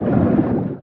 File:Sfx creature pinnacarid swim fast 04.ogg - Subnautica Wiki